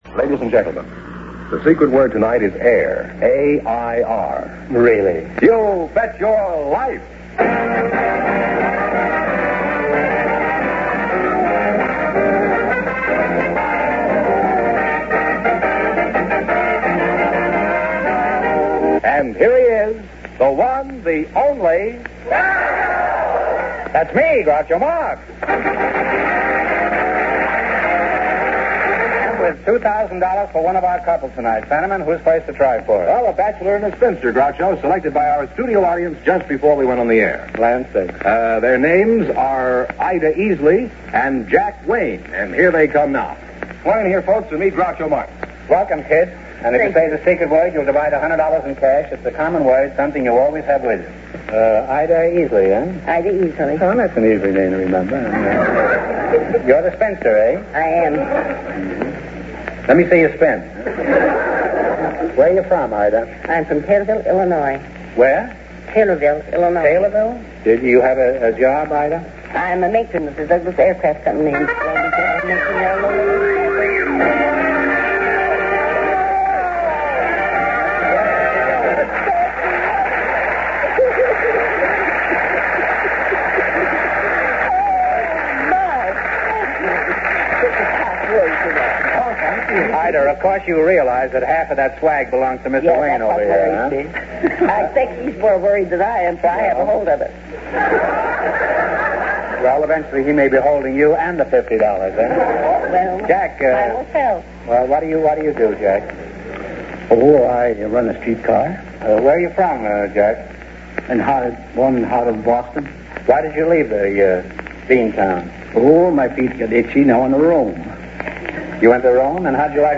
You Bet Your Life Radio Program, Starring Groucho Marx